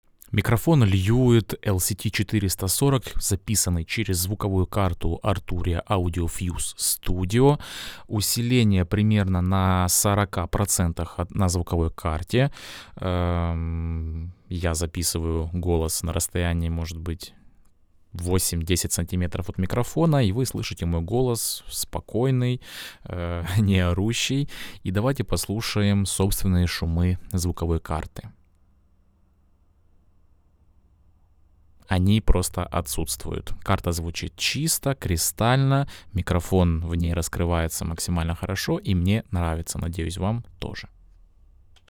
arturia_audiofuse_studio_golos_na_mikrofon_lewitt_440.mp3